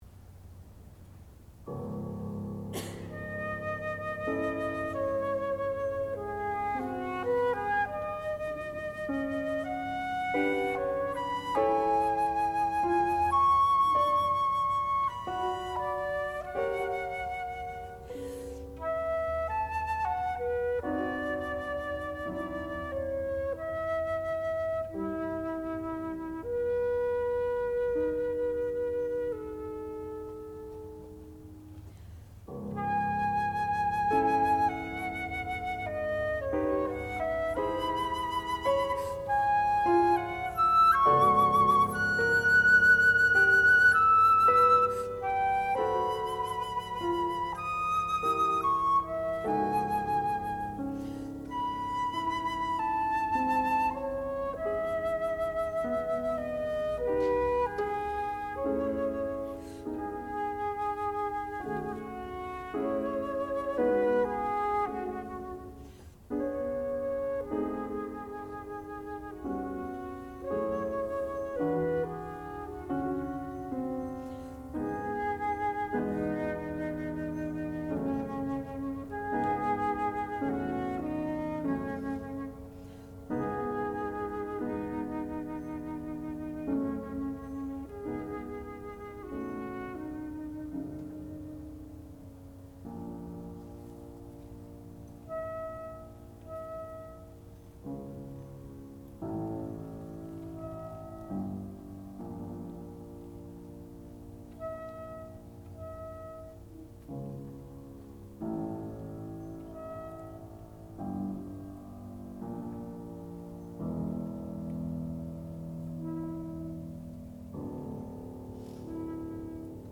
classical music
piano
flute
Advanced Recital